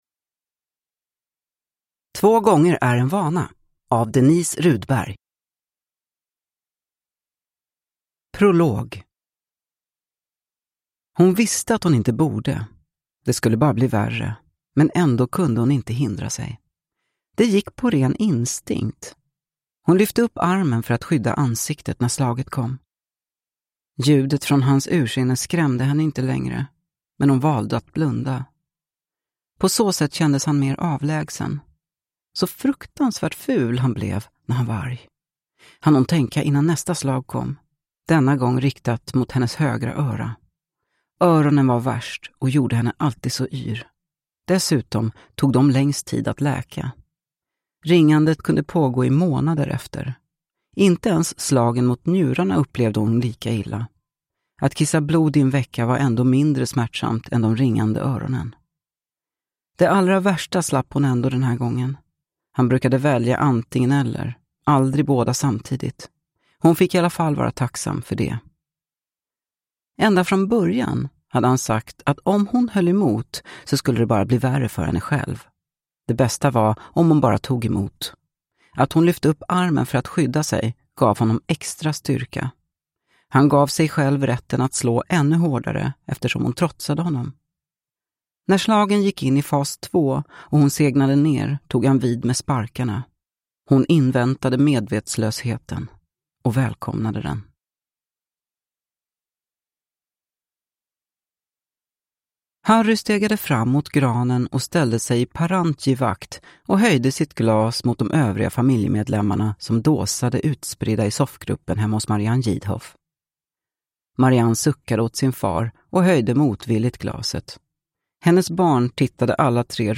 Två gånger är en vana – Ljudbok